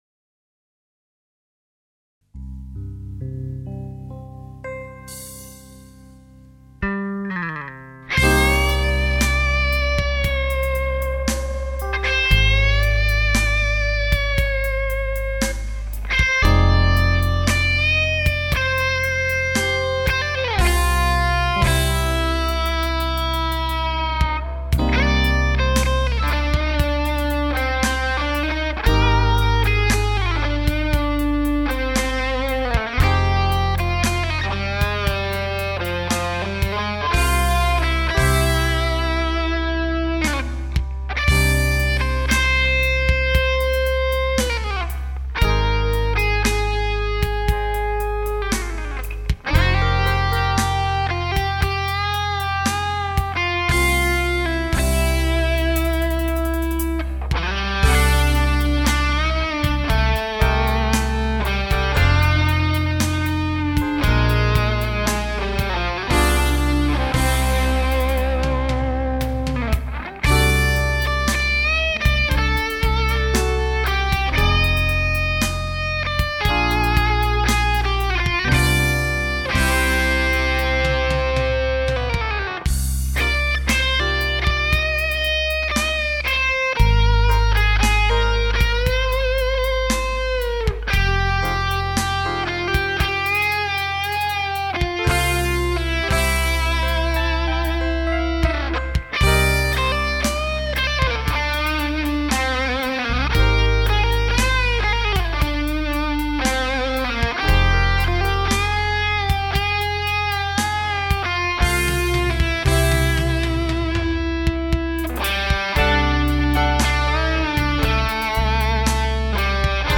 Plexi 50 demo tunes